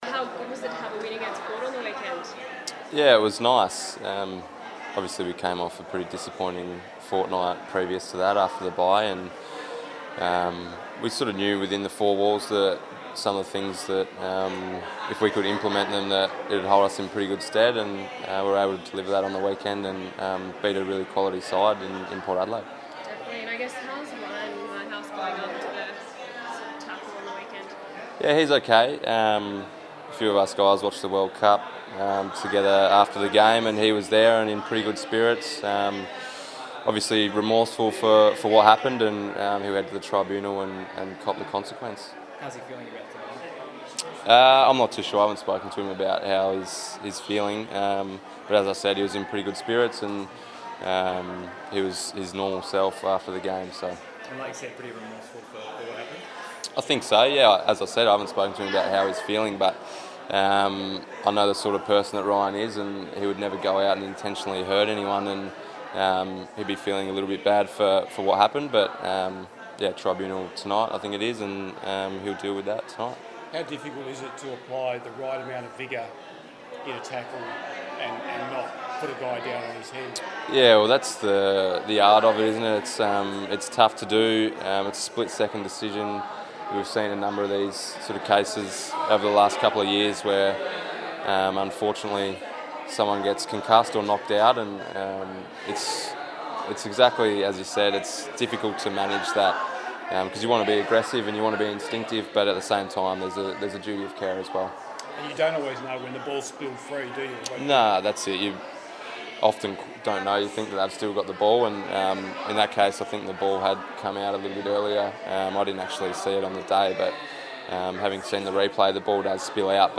Lachie Neale spoke to media at the opening of the Starlight Express Room at Perth Children's Hospital.